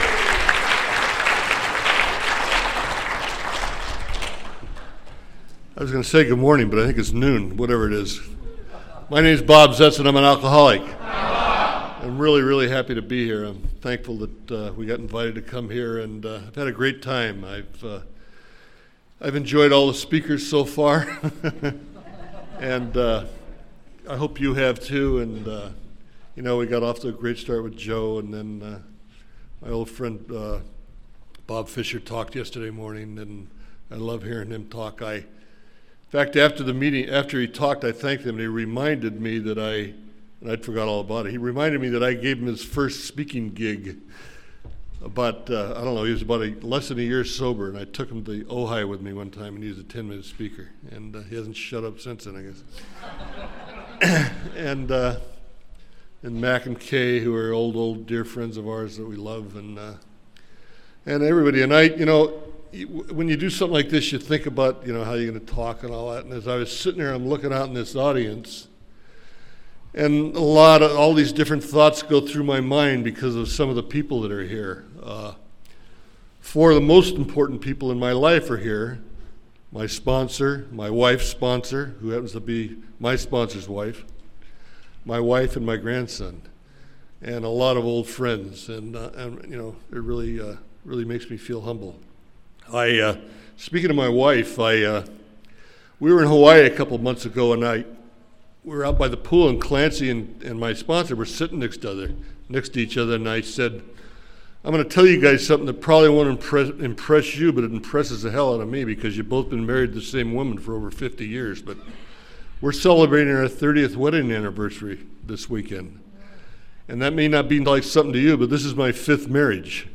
Thousand Oaks CA - Sunday AA Speaker